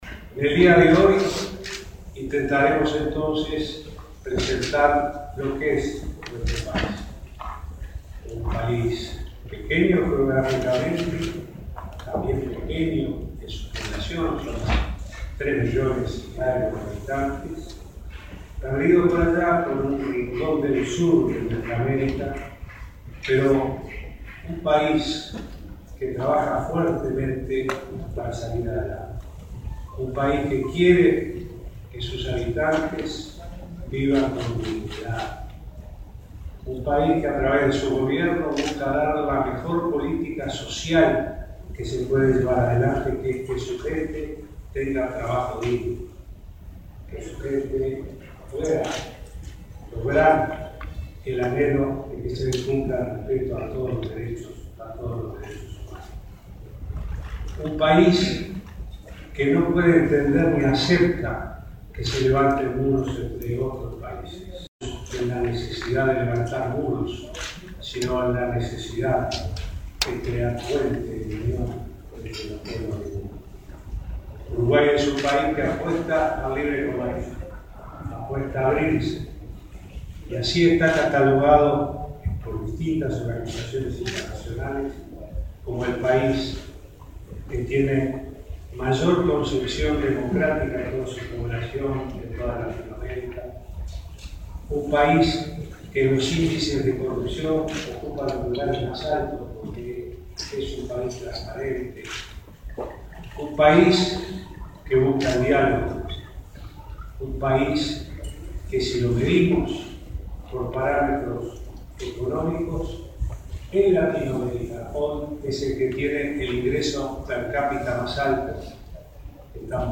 El presidente Vázquez inauguró este viernes el evento Día de Uruguay en la Cámara de Comercio de Hamburgo. En ese marco, repasó algunos de los parámetros que hacen de Uruguay un buen país para invertir, habló del Plan de Infraestructura y del cambio de la matriz energética.